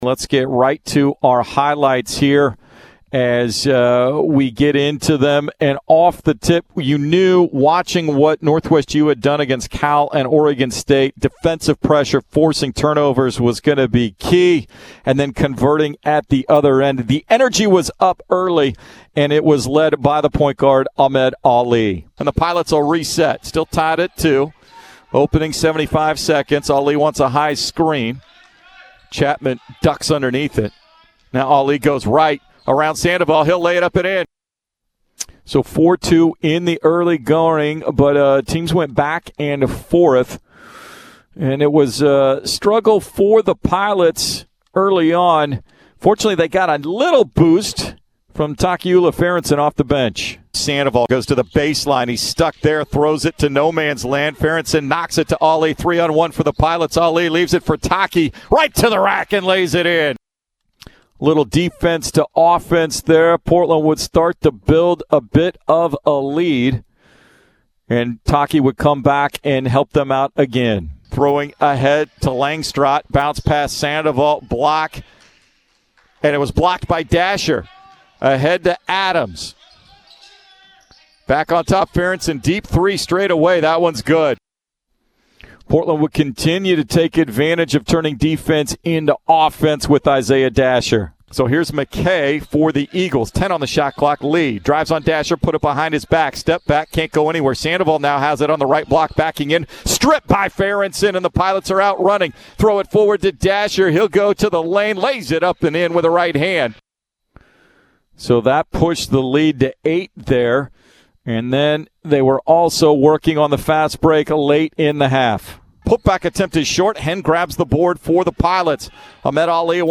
November 29, 2020 Post-game radio highlights from Portland's 74-69 win against Northwest U in the final game of the U.S. Bank Portland Invitational on Sunday, Nov. 29, 2020. Courtesy of 910 ESPN-Portland (KMTT)